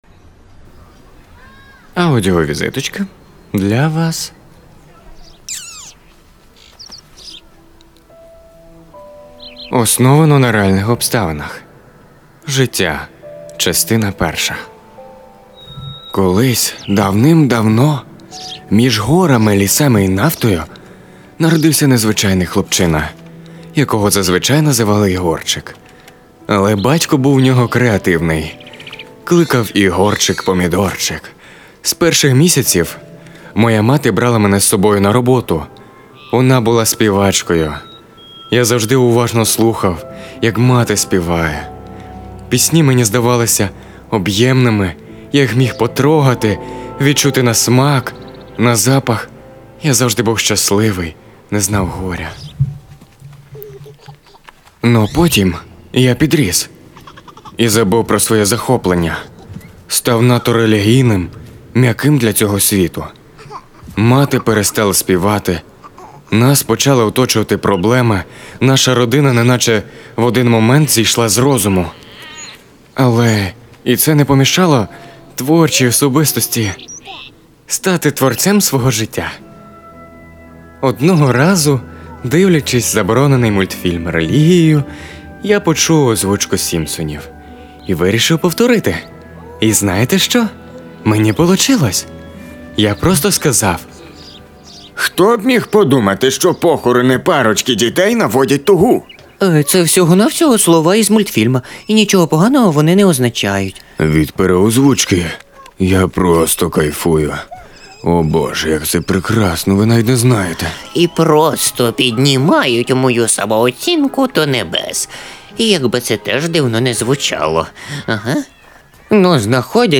шось подыбне до озвучки.mp3
Чоловіча
Баритон Бас